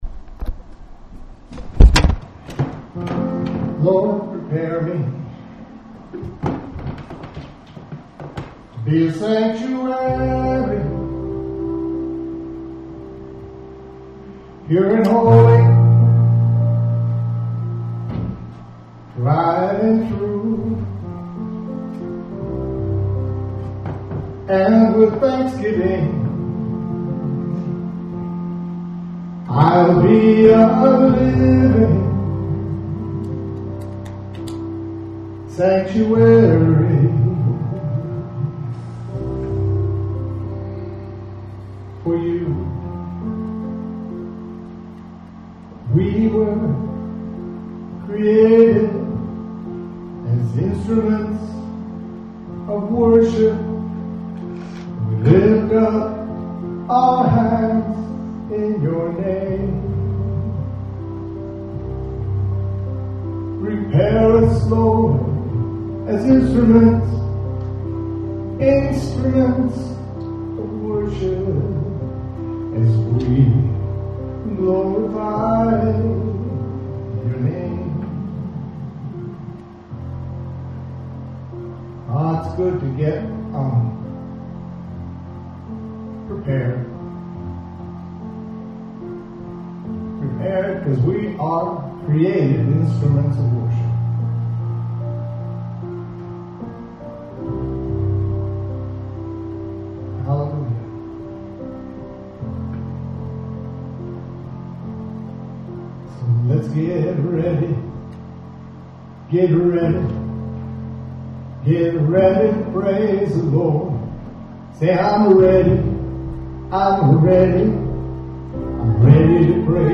WORSHIP 817.mp3